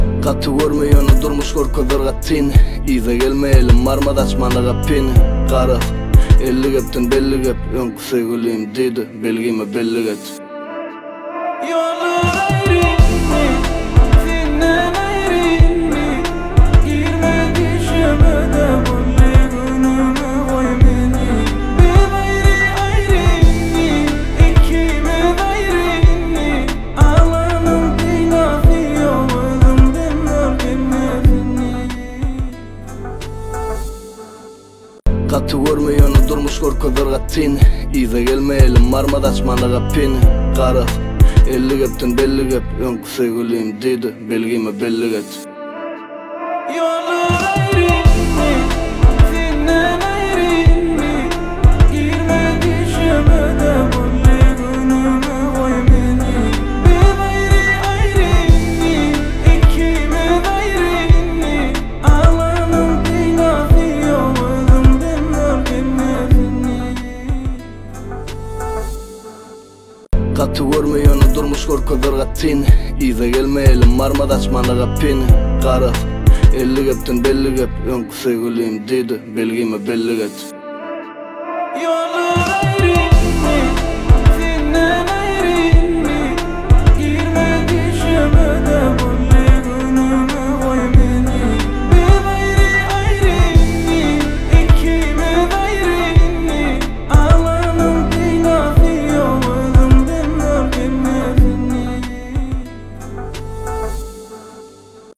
Туркмен музыка